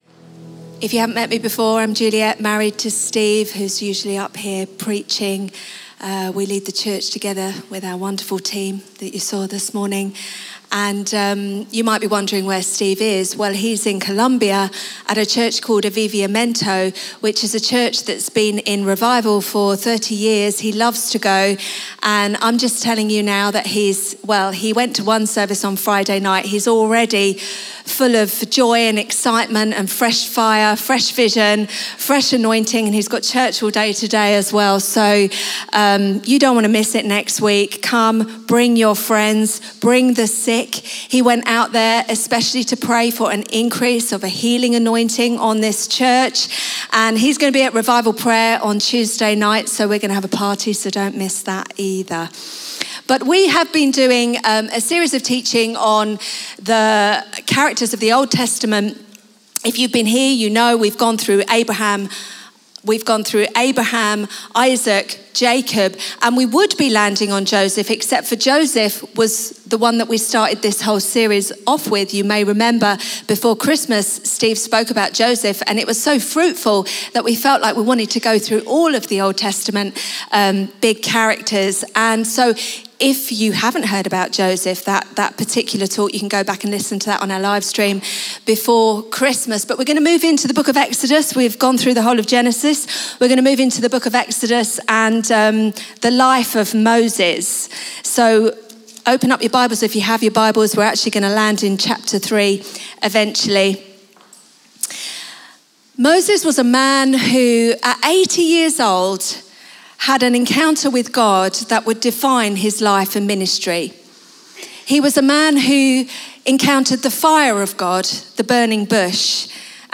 Chroma Church - Sunday Sermon People of the Presence Mar 30 2023 | 00:30:54 1x 00:00 / 00:30:54 Subscribe Share RSS Feed Share Link Embed